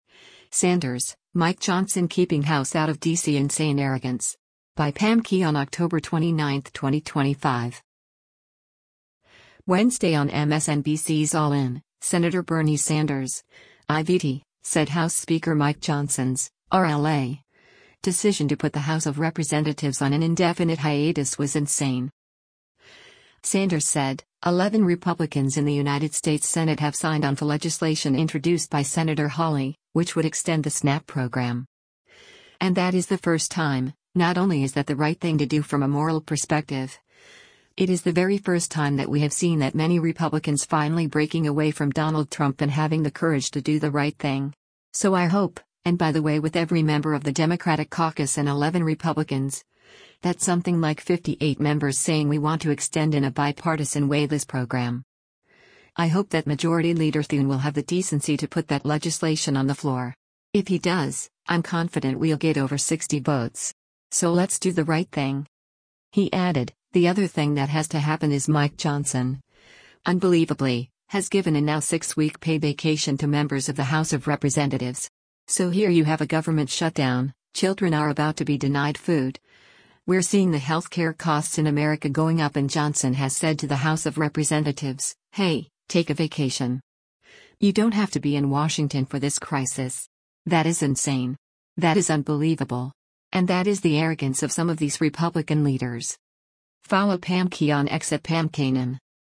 Wednesday on MSNBC’s “All In,” Sen. Bernie Sanders (I-VT) said House Speaker Mike Johnson’s (R-LA) decision to put the House of Representatives on an indefinite hiatus was “insane.”